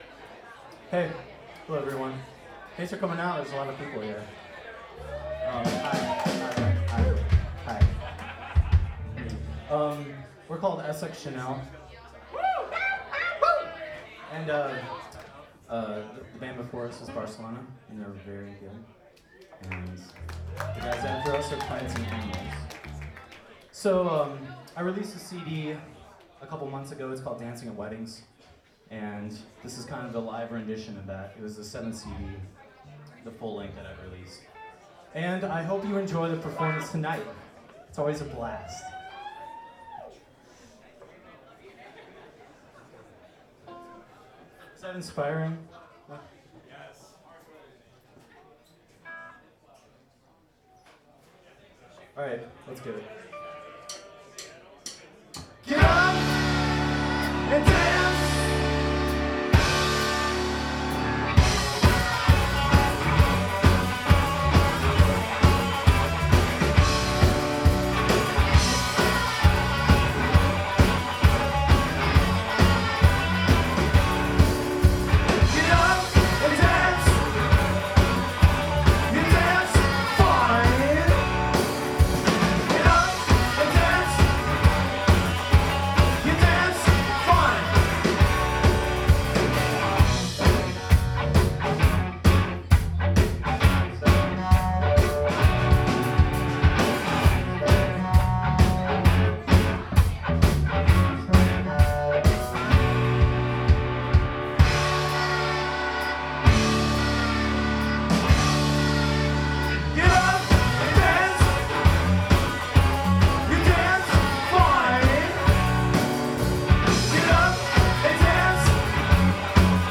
Schuba's Tavern
full band